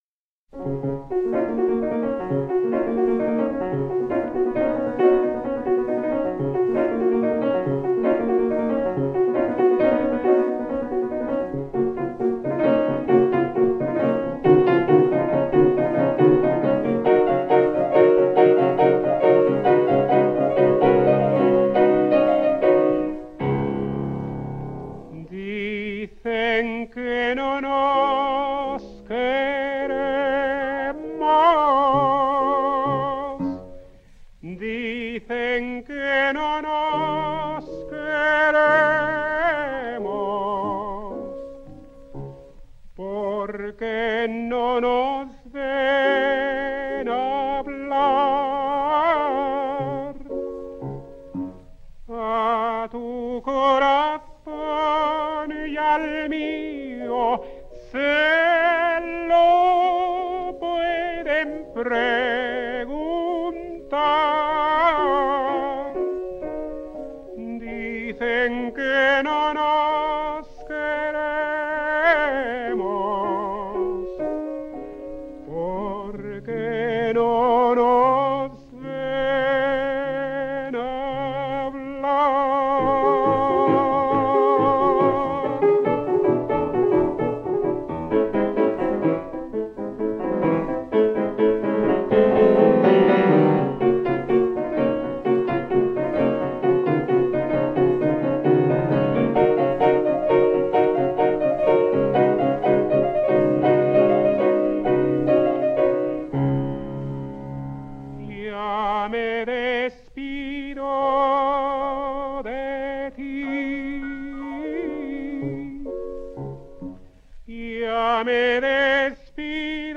Néstor Chayres singsSiete canciones populares españolas:
The Mexican tenor Néstor Mesta Chayres was born in Lerdo in the state of Durango on 26 February 1908.
His voice is of a beautiful quality, and is used with intelligence and musicianship.